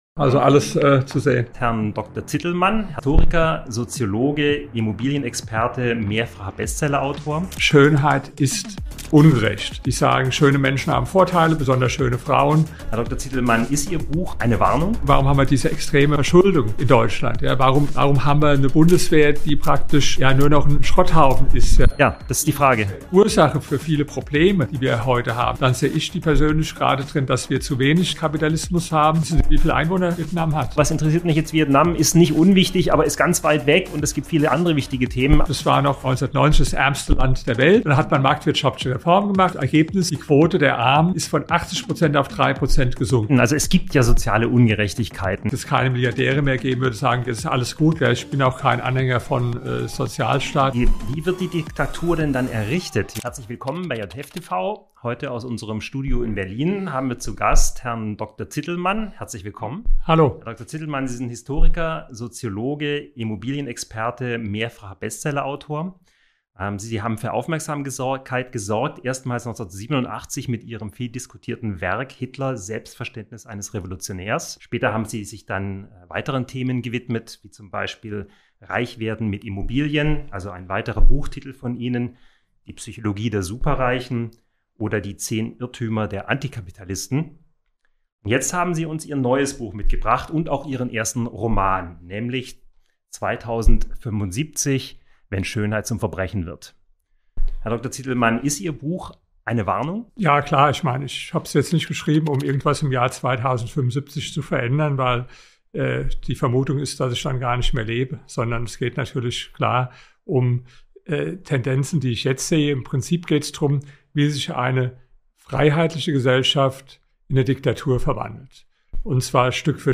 Episode #248 - Interview bei JUNGE FREIHEIT